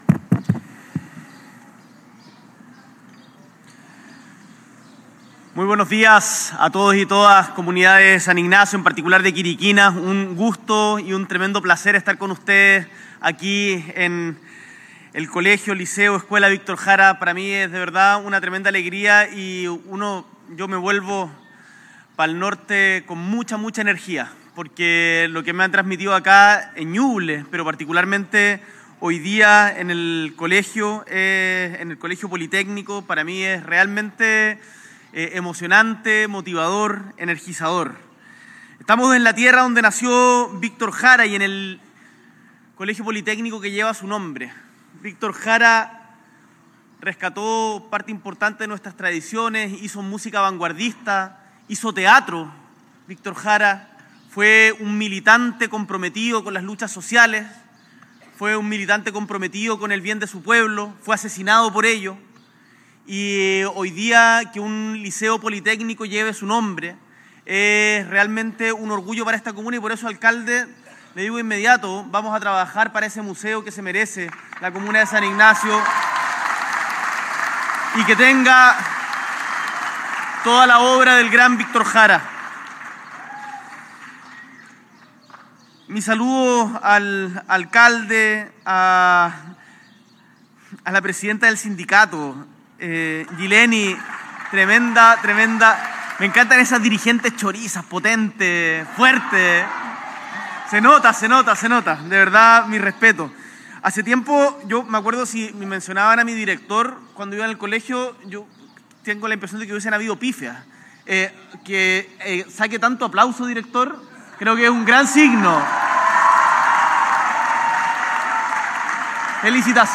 S.E. el Presidente de la República, Gabriel Boric Font, encabeza la entrega de las obras de conservación del Colegio Bicentenario Víctor Jara en San Ignacio
Audio Discurso